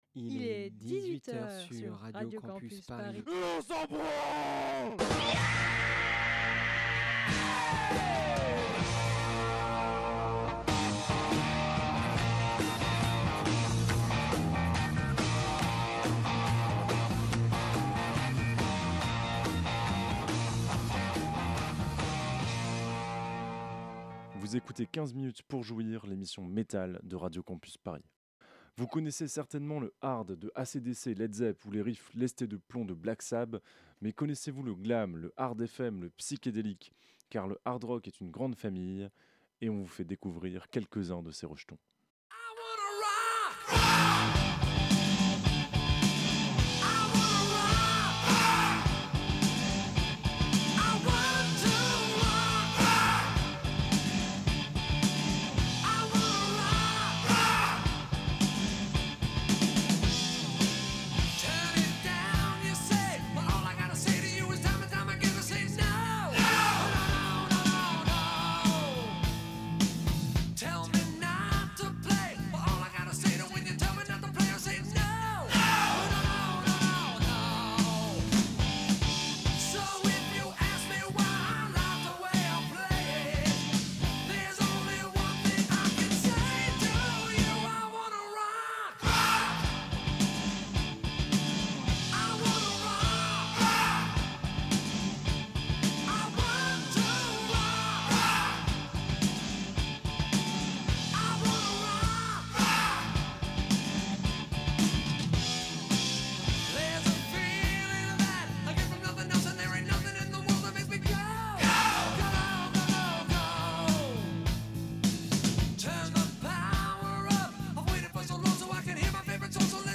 Le Hard Rock
Autant te prévenir, ça va être rock, ça va être hard. Dur comme une guitare saturée, comme un chant glaireux formé à la clope et au gin, comme un martèlement de batterie qui ne laisse aucune place au doute.